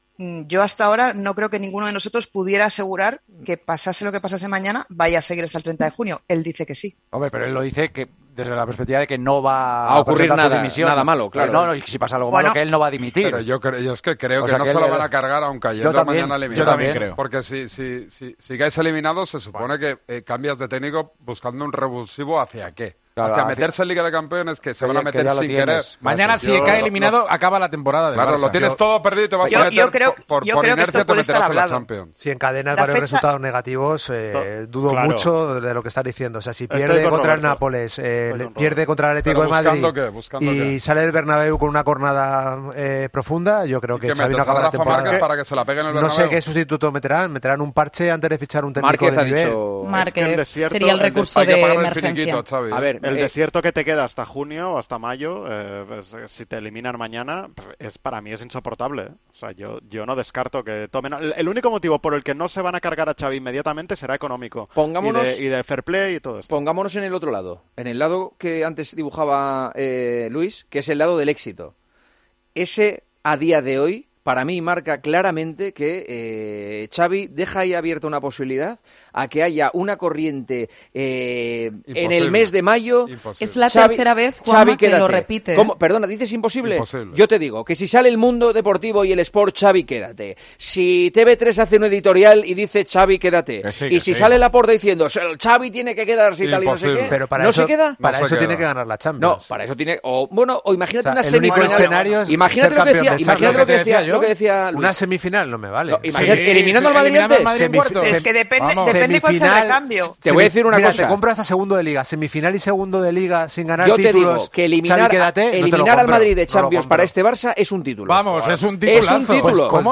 El presentador de El Partidazo expuso una serie de acontecimientos que podrían hacer cambiar de opinión a Xavi y rectificar la decisión que tomó de abandonar el Barcelona.